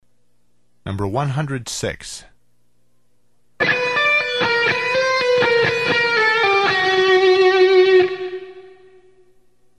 Escala menor natural com a tônica na quinta corda